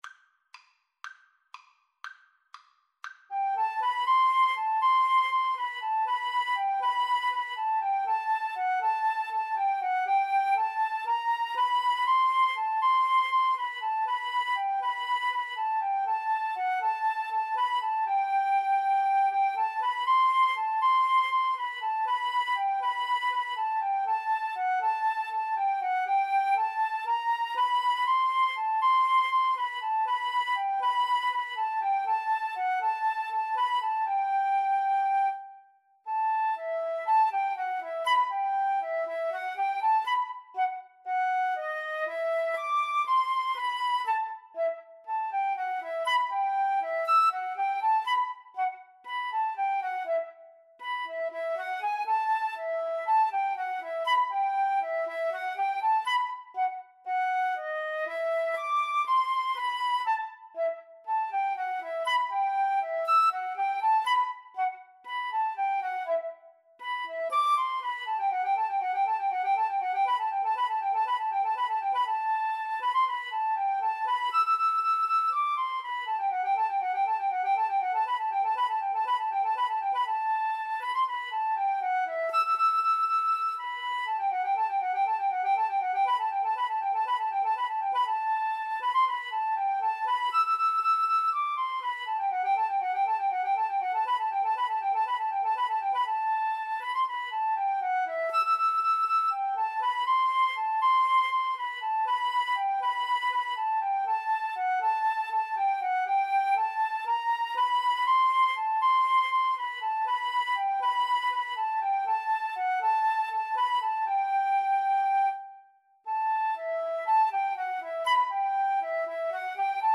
Fast Two in a Bar =c.120
Flute Duet  (View more Intermediate Flute Duet Music)